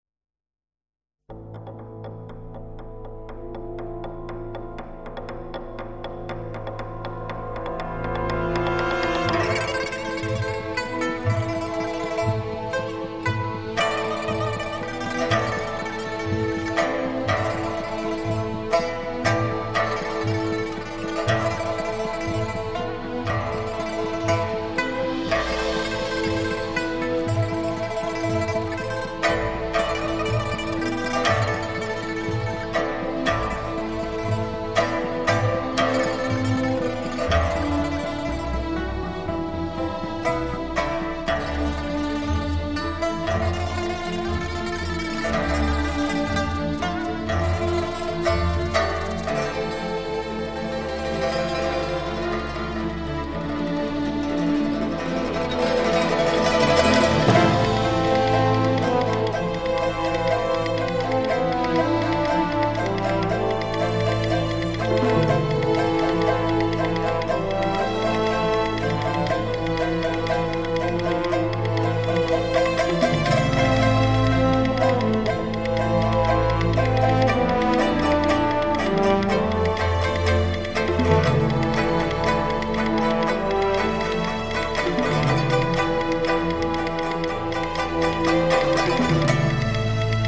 在中國中央電視臺的 480 平米類比錄音棚運用頂級 DSD 錄音設備錄製